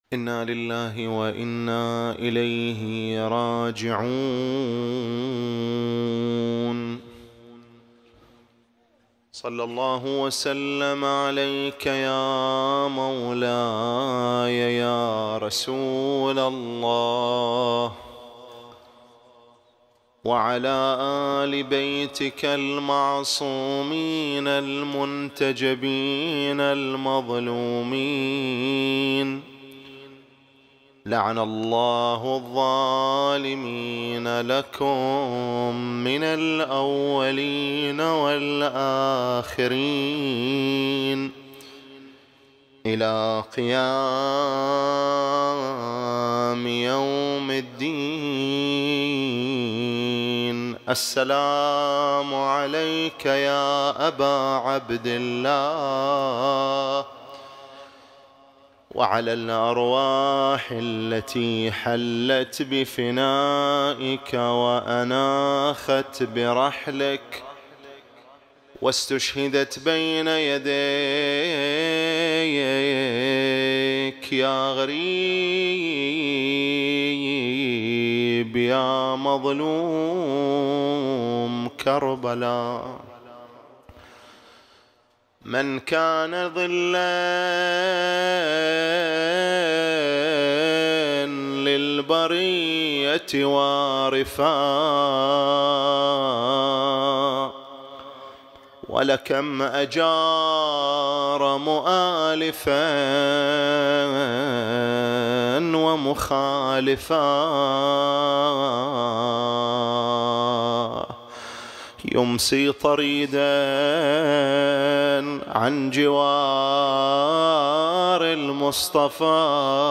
الليلة الثانية من محرم الحرام 1447 هـ| حسينية السيد الخوئي بسنابس